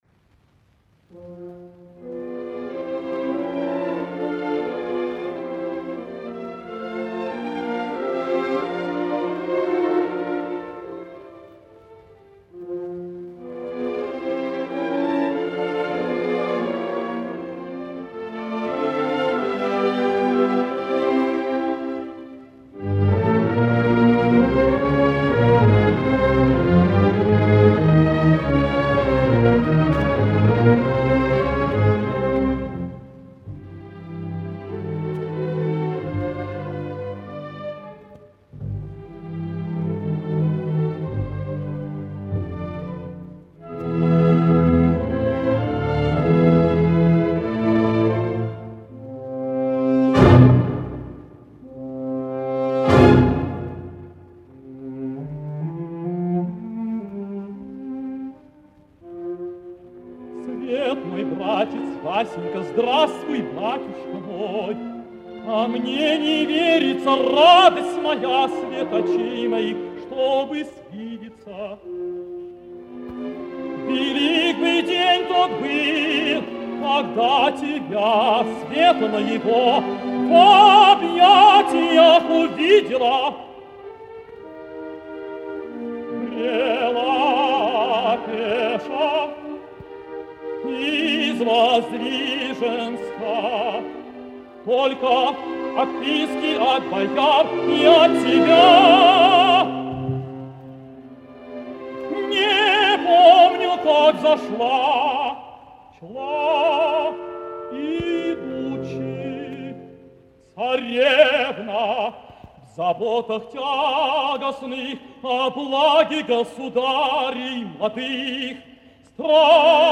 опера "Хованщина" в MP3
бас
тенор
баритон
меццо-сопрано